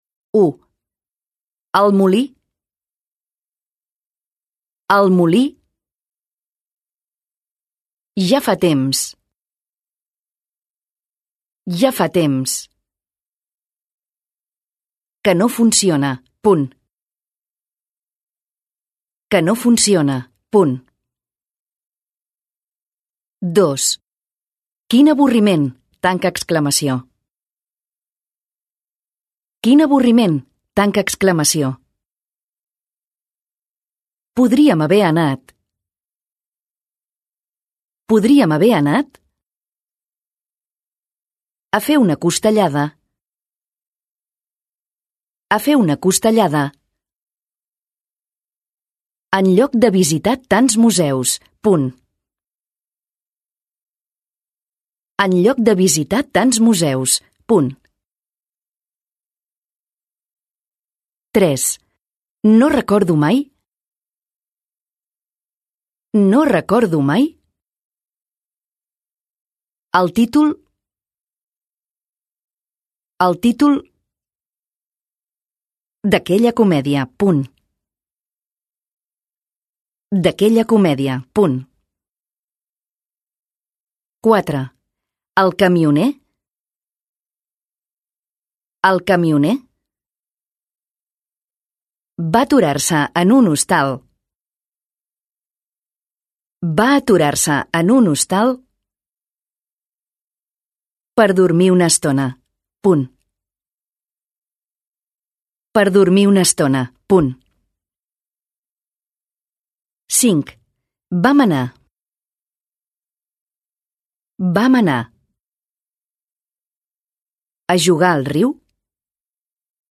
Dictat